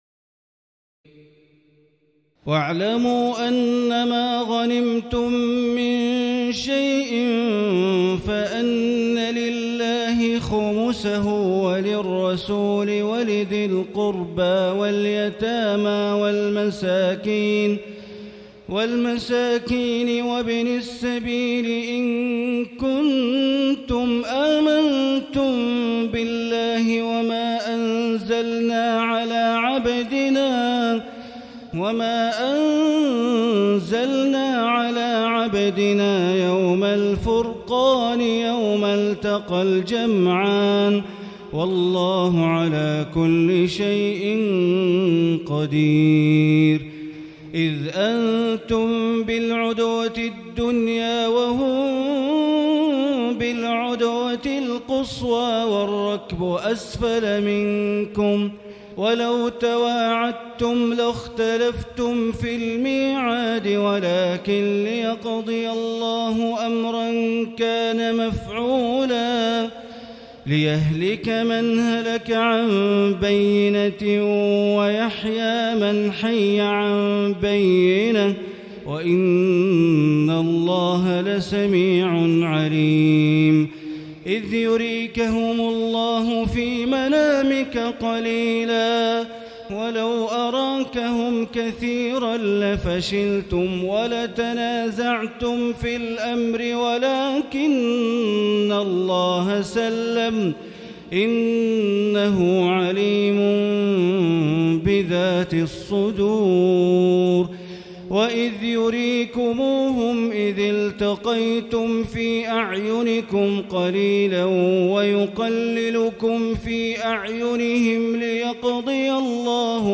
تراويح الليلة التاسعة رمضان 1439هـ من سورتي الأنفال (41-75) و التوبة (1-33) Taraweeh 9 st night Ramadan 1439H from Surah Al-Anfal and At-Tawba > تراويح الحرم المكي عام 1439 🕋 > التراويح - تلاوات الحرمين